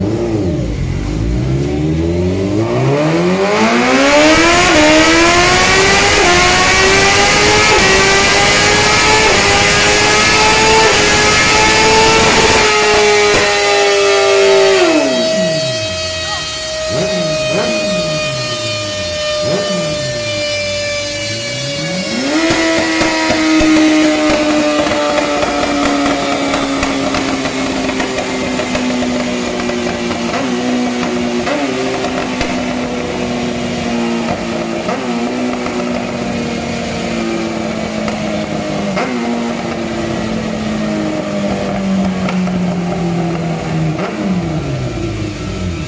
This is how a Suzuki pulls on 110 000kms